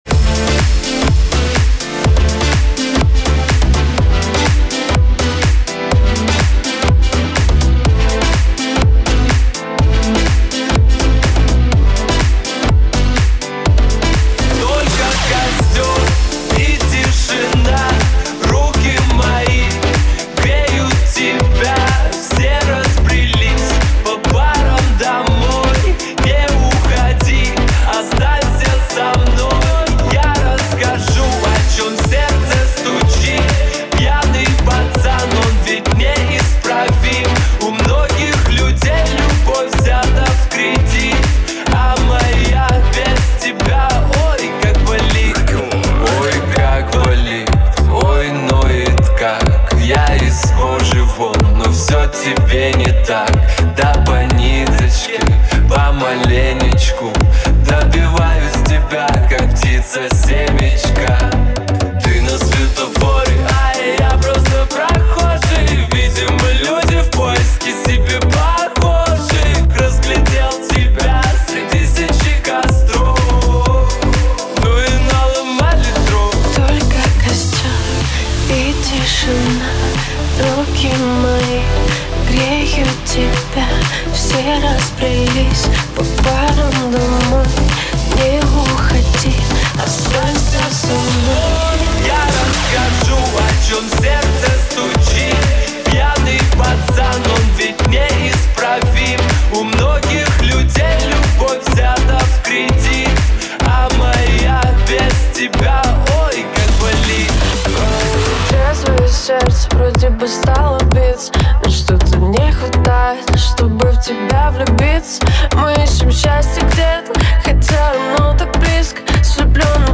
это яркий трек в жанре поп с элементами электронной музыки.